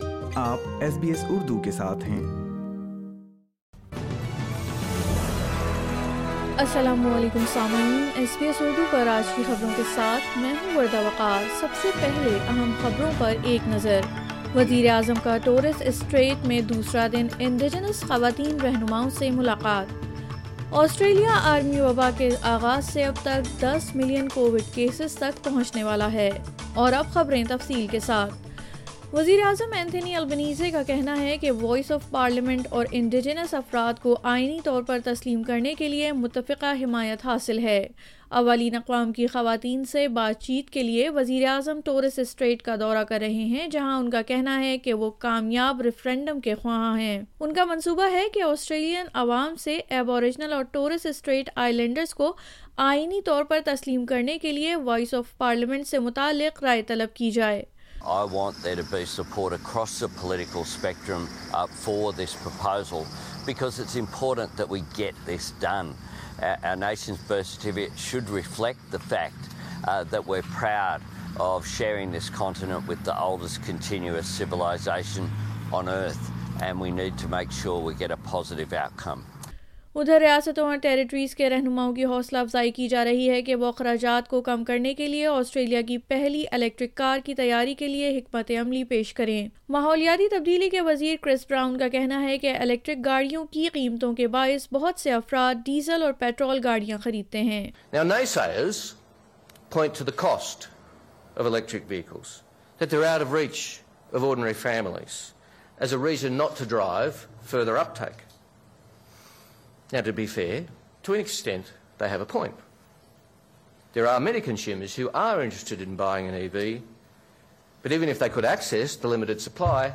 Urdu News Friday 19 August 2022